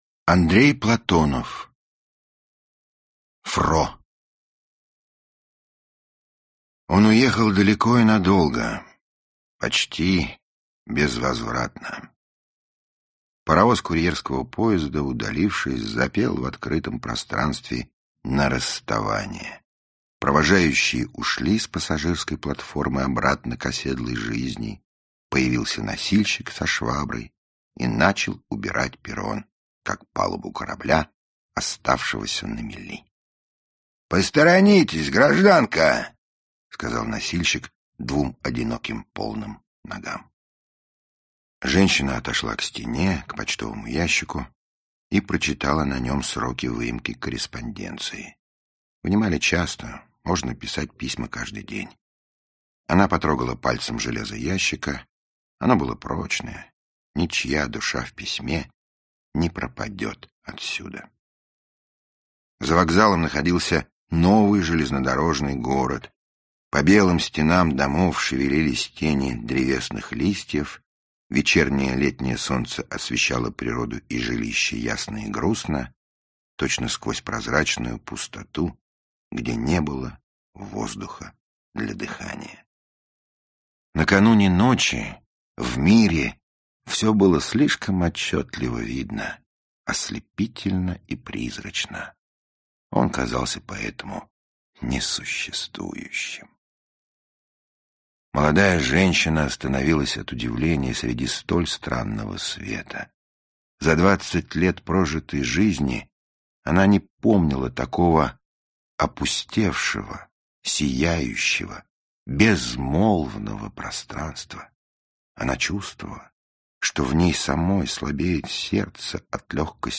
Аудиокнига Фро | Библиотека аудиокниг
Aудиокнига Фро Автор Андрей Платонов Читает аудиокнигу Михаил Горевой.